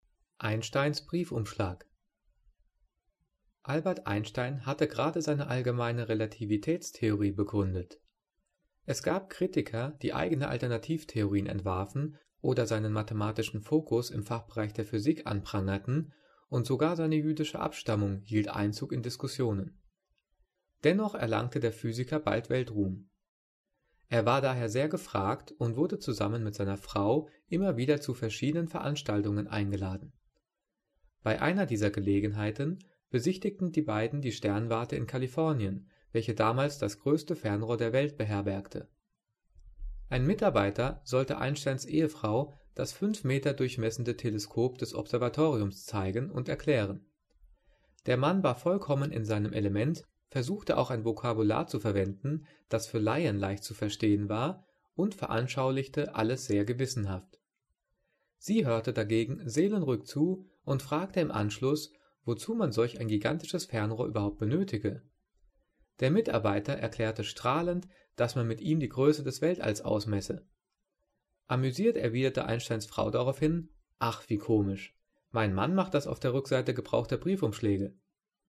Diktat: "Einsteins Briefumschläge" - 7./8. Klasse - Dehnung und Schärfung
Gelesen: